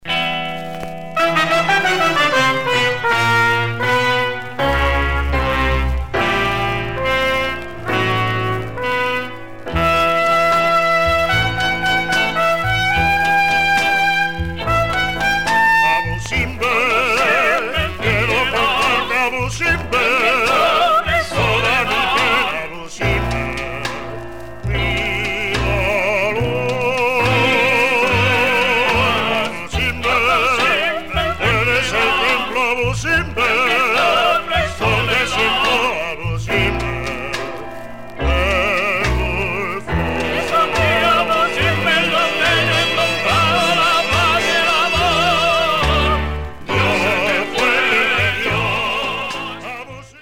Classic Egyptian 60's 7'
light and easy, sounds a bit like a Walt Disney thing.